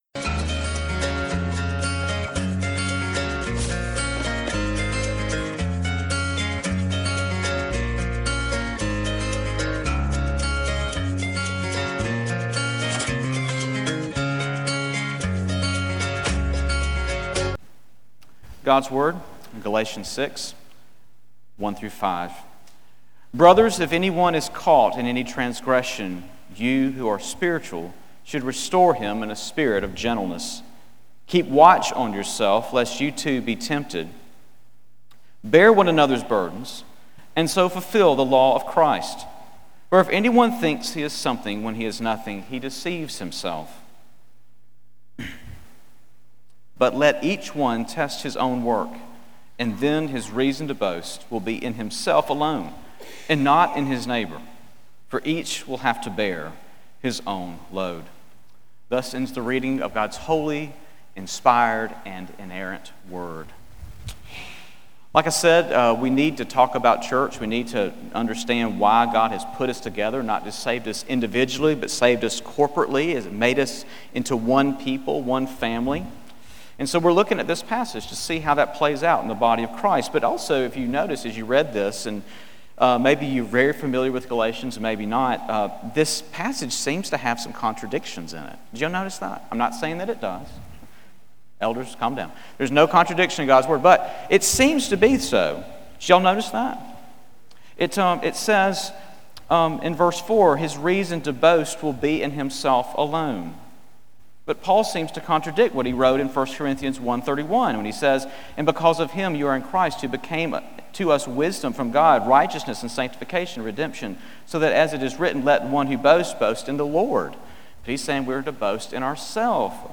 Sermon on Galatians 6:1-5 from July 6